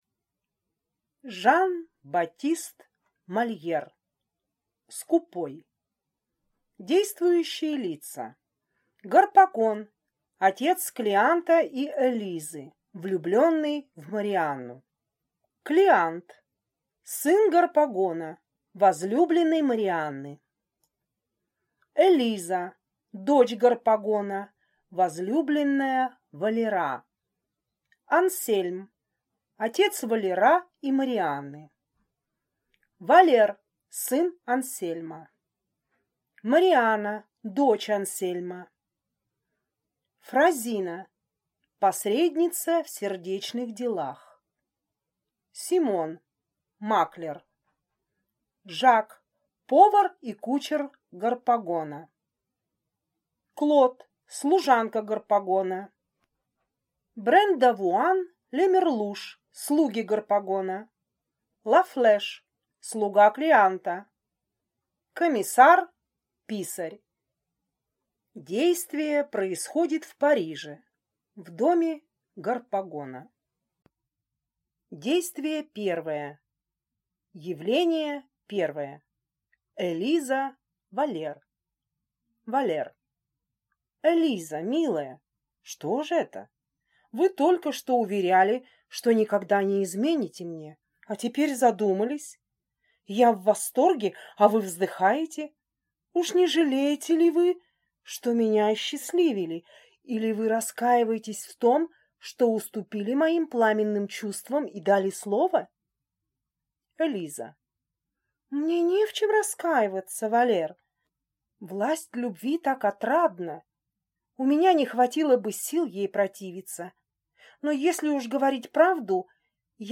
Аудиокнига Скупой | Библиотека аудиокниг